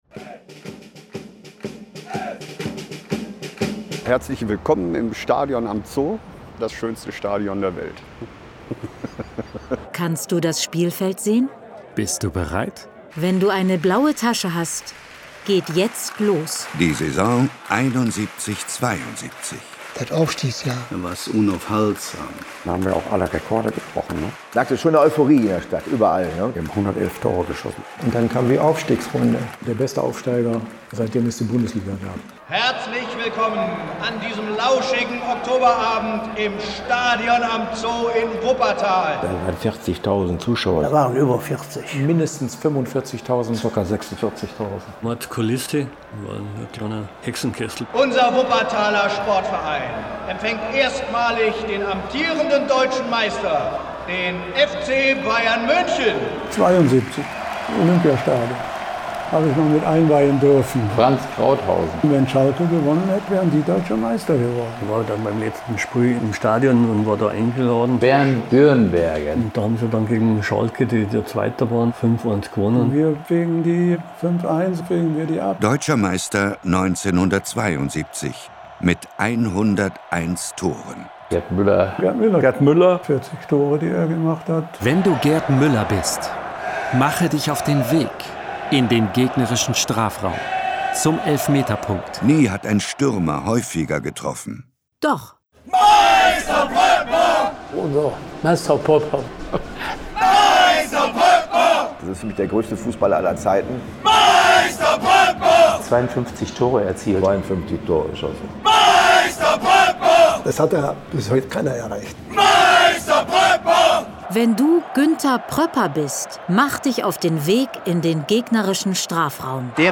Ein kurzes WuppertalMünchen1972-Demo mit vielen damals beteiligten Spielern im O-Ton.